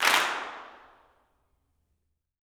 CLAPS 17.wav